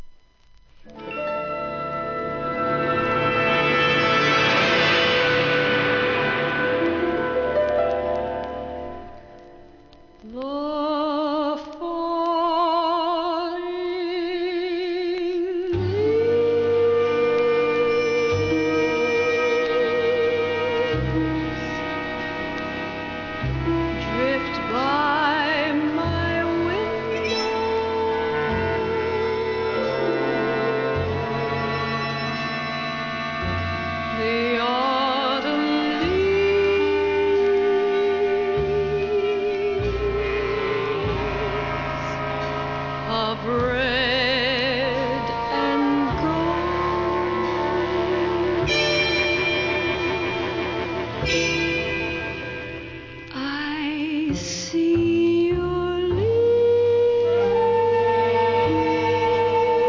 当時最先端の革新的なアレンジ力で圧倒！！